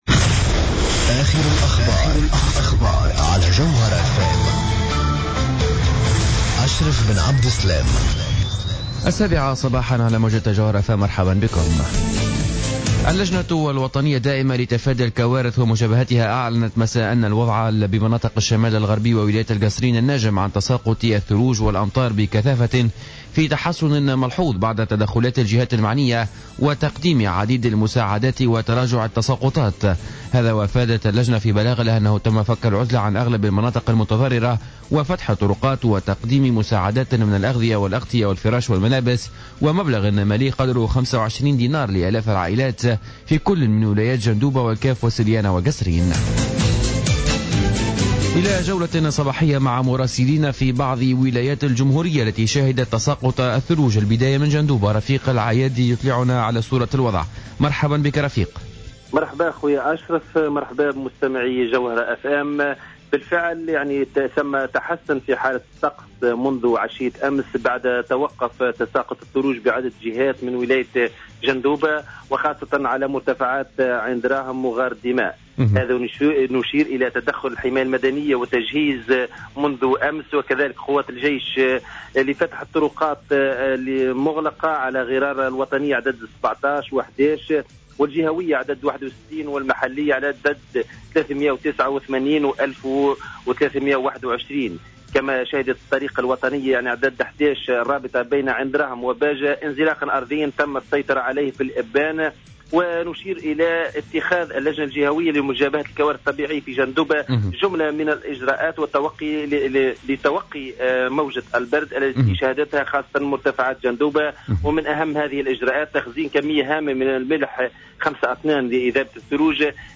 نشرة اخبار السابعة صباحا ليوم الجمعة 02-01-15